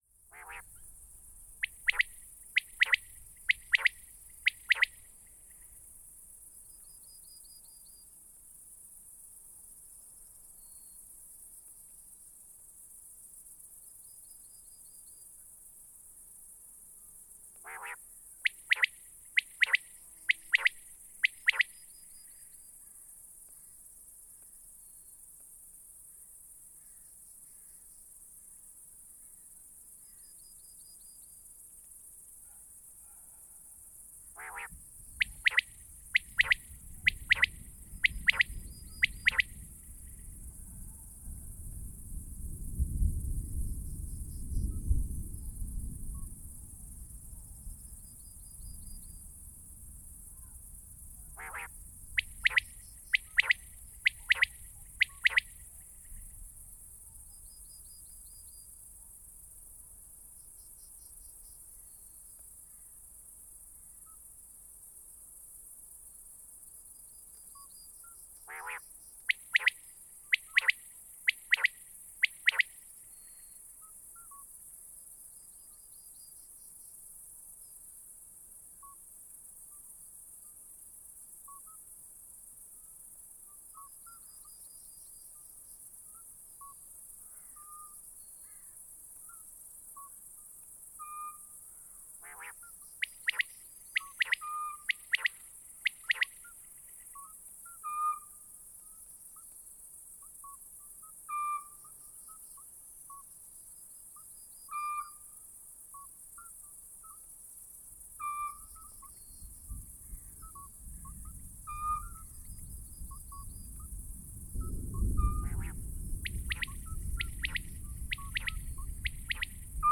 ユニーク且つ小気味良いサウンドが満載。
Ce quatrième opus de la collection « Galets sonores » regroupe une série d’enre-gistrements sur le thème des oiseaux, collectés dans différentes régions de France métropolitaine entre 2011 et 2024.